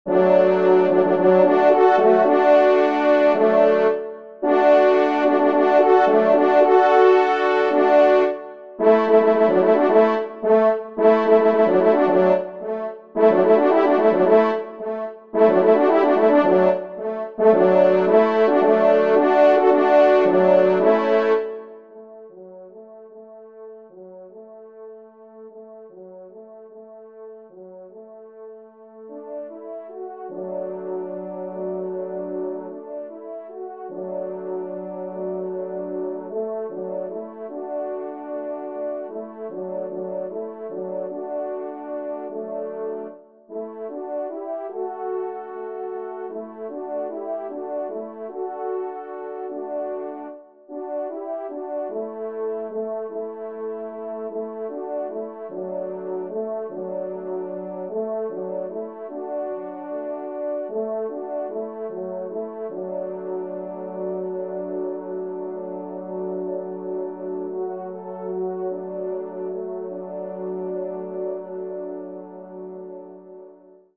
2e Trompe